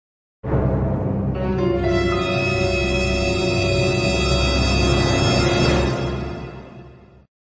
Звуки эмодзи
Вот это поворот неожиданная новость саспенс взрыв мозга